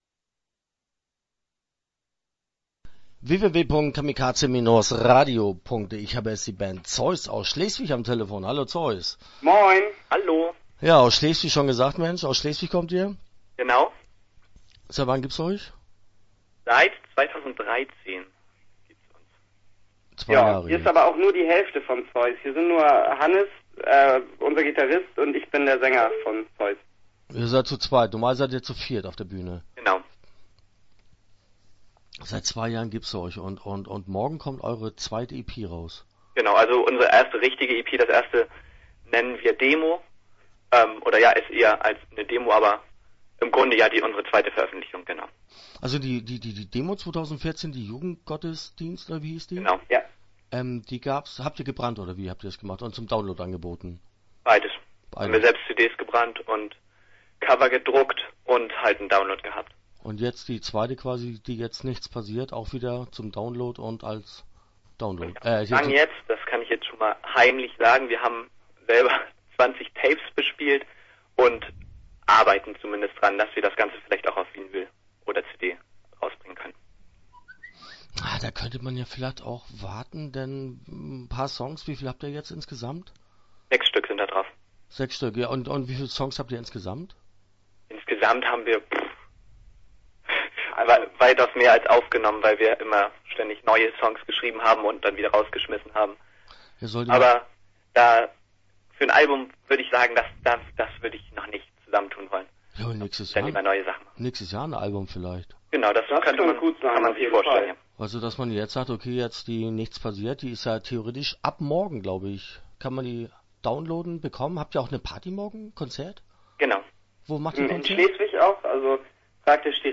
Zoi!s - Interview Teil 1 (11:43)